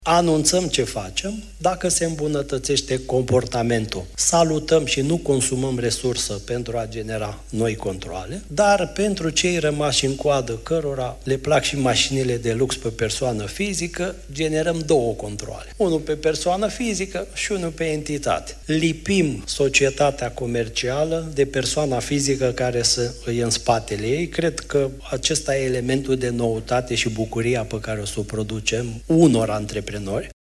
Șeful Fiscului, Adrian Nica, a făcut declarații la Conferința anuală privind taxele, organizată de o firmă de consultanță, Price Waterhouse Coopers.
Președintele ANAF, Adrian Nica: „Pentru cei cărora le plac și mașinile de lux pe persoană fizică, generăm două controale”